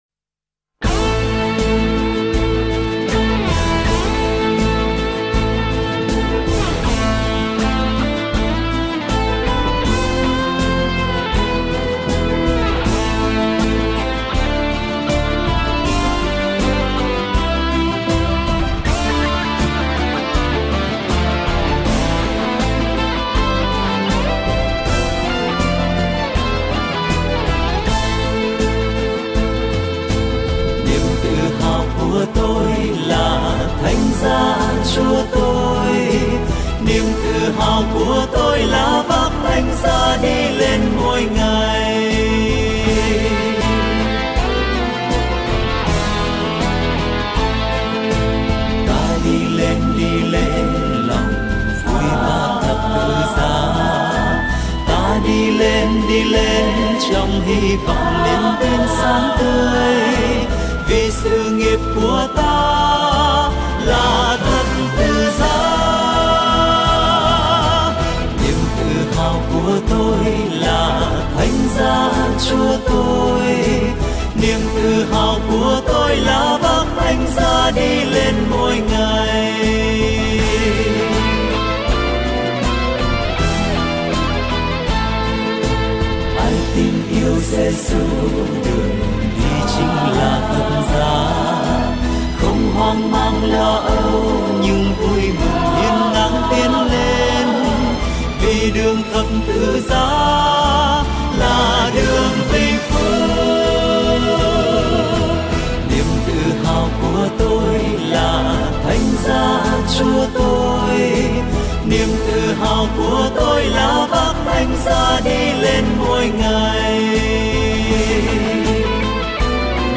ca khúc